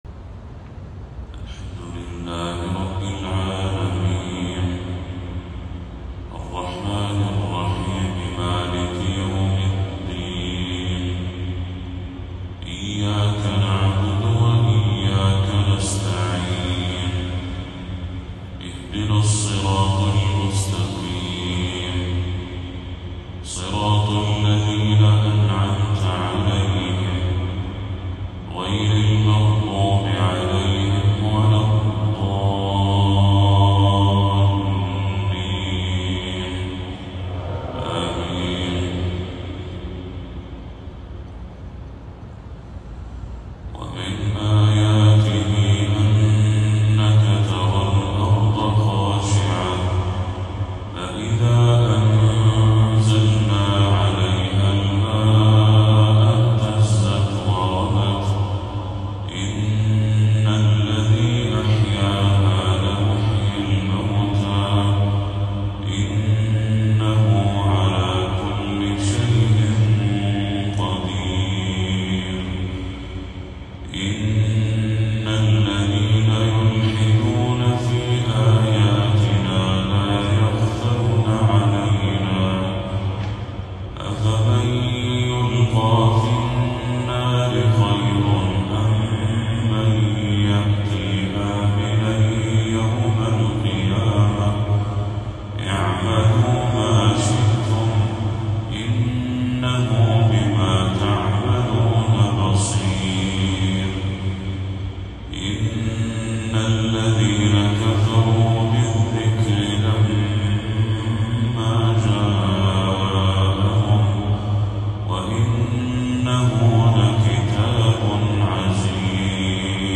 فجر 8 صفر 1446هـ